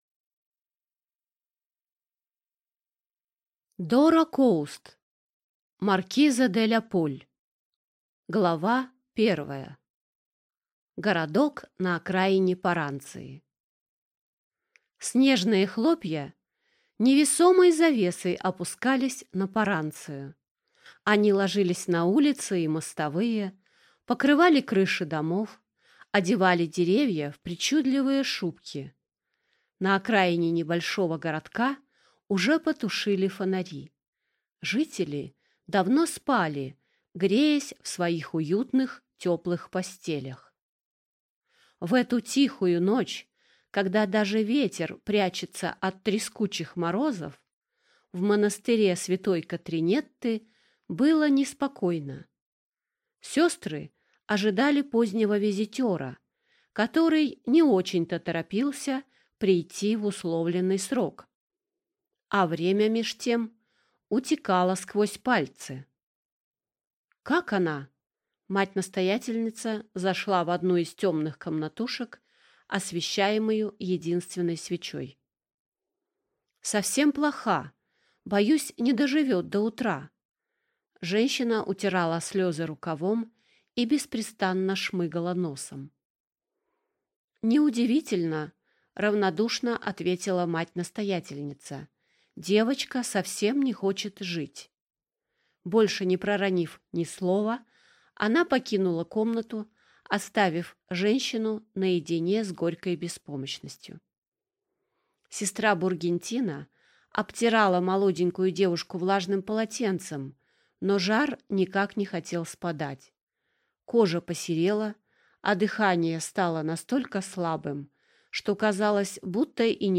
Аудиокнига Маркиза де Ляполь | Библиотека аудиокниг